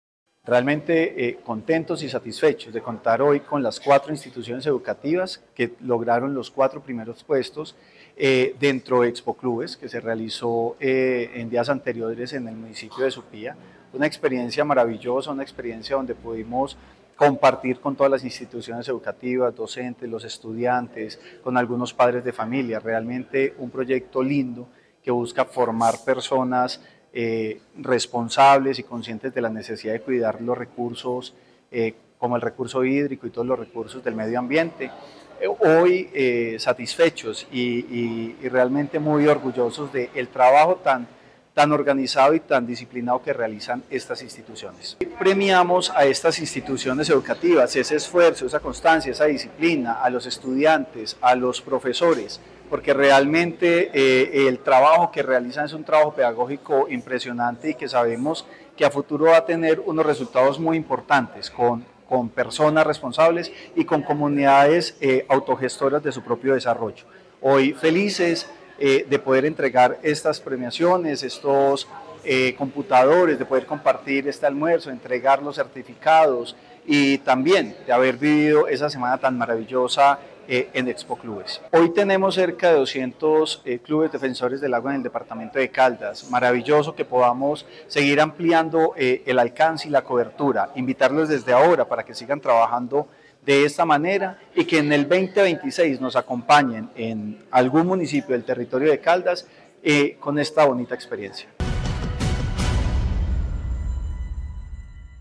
Francisco Javier Vélez Quiroga, secretario de Vivienda de Caldas.